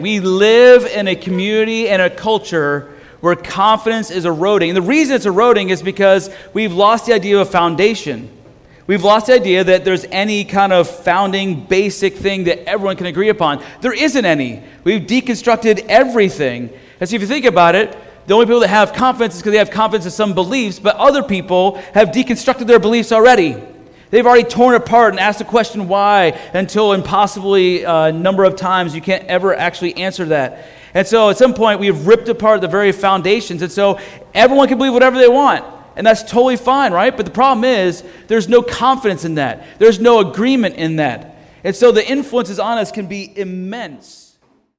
Sermon 2017-08-06: Confidence in Christ